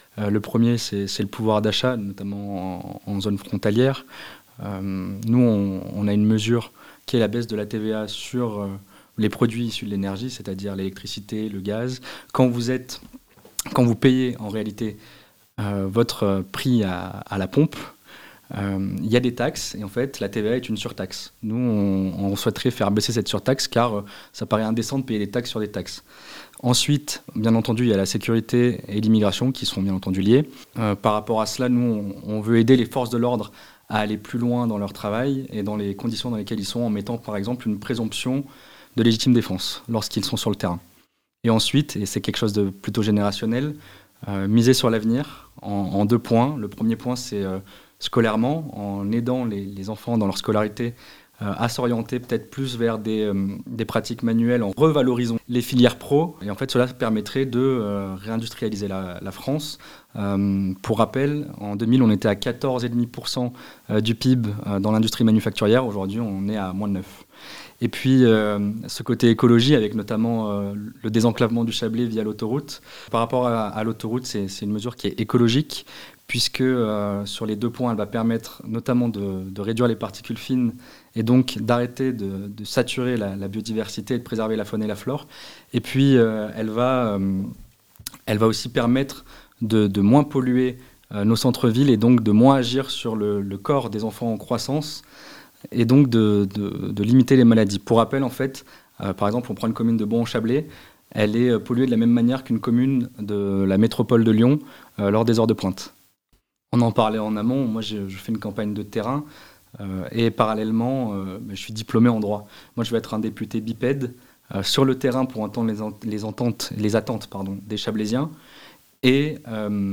Gros plan sur les candidats du Chablais (interviews)
Voici les interviews des 8 candidats de cette 5ème circonscription de Haute-Savoie (par ordre du tirage officiel de la Préfecture)